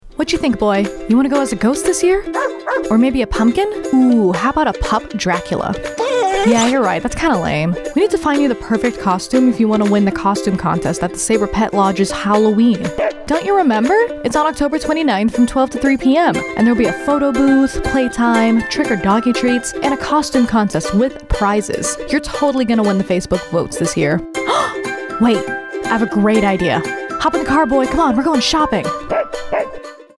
A 30 second spot for AFN Spangdahlem advertising an event at the base pet lodge.